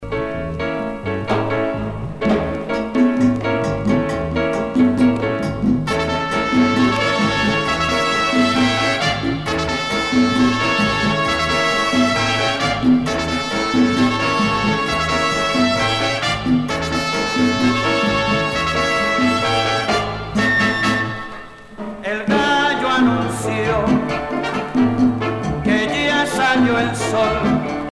danse : cha cha cha
Pièce musicale éditée